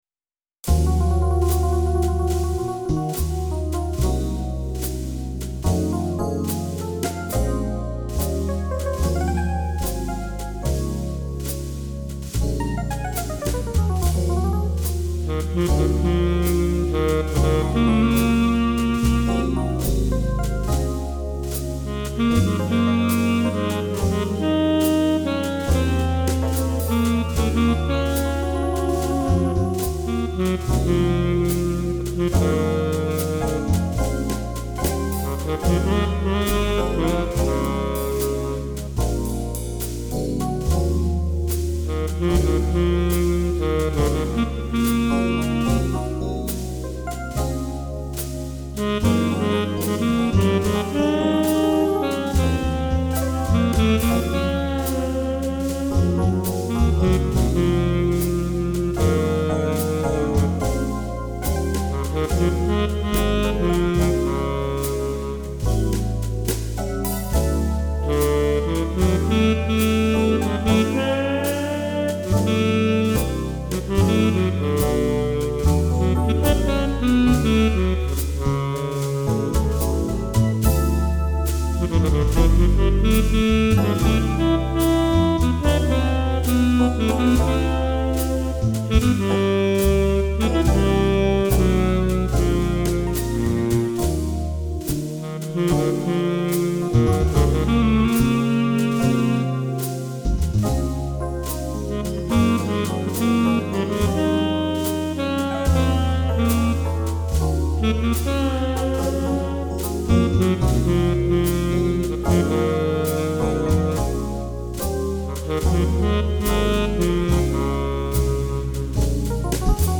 This is late night stuff here.
jazz standards